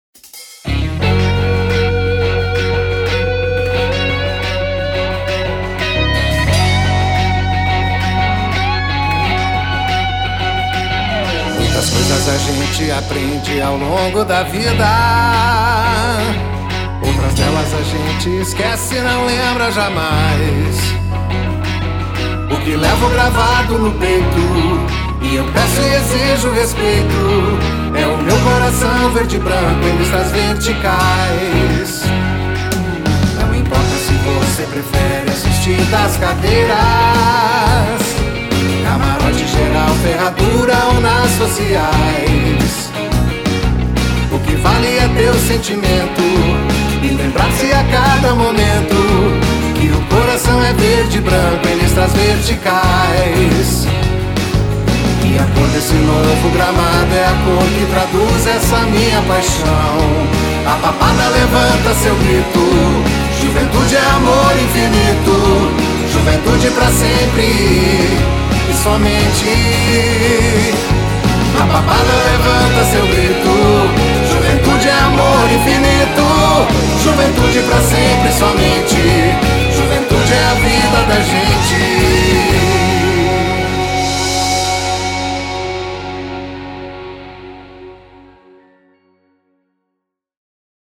Hinos